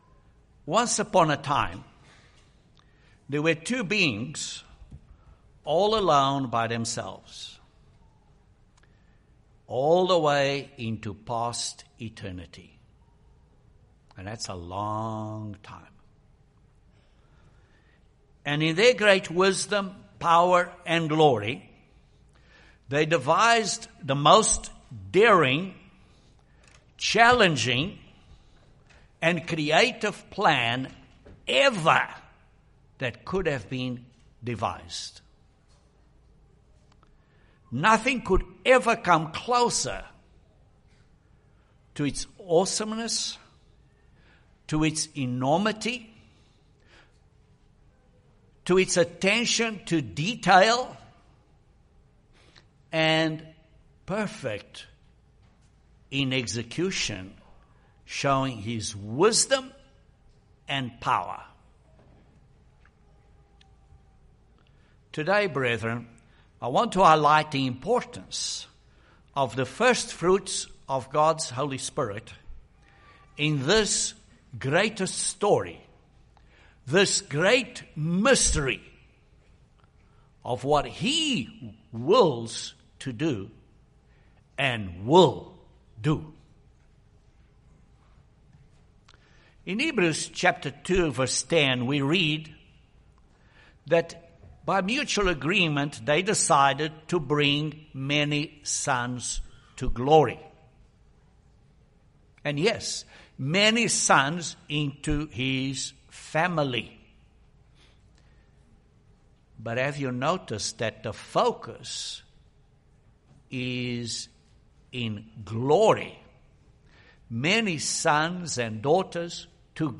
This sermon looks at the firstfruits of the Spirit and how that symbolism is completely fulfilled by the day of Pentecost.